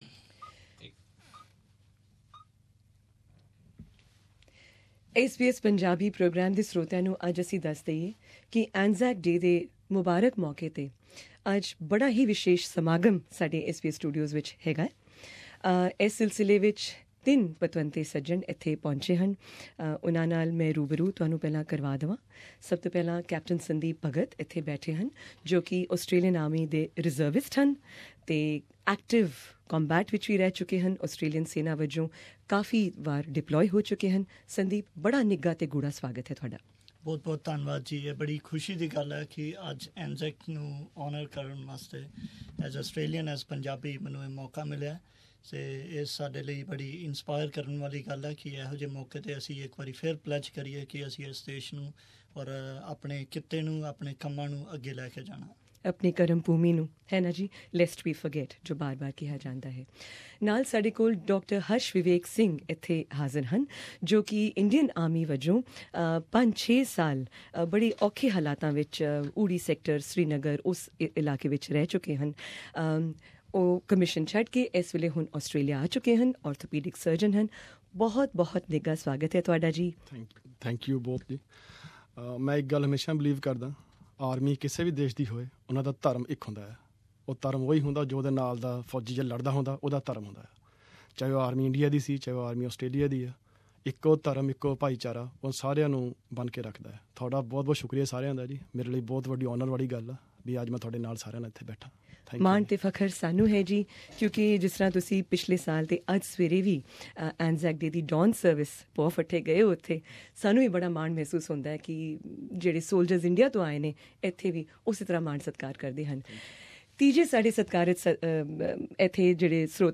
Presenting here, the audio of the full interview with all of our guests, which was also streamed live as a video on SBS Punjabi Facebook page.